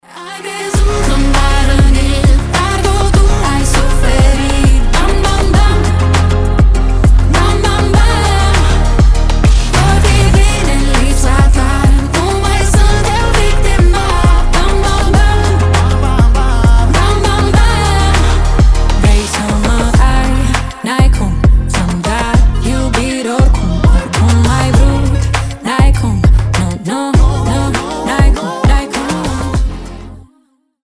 Танцевальные рингтоны
Dance pop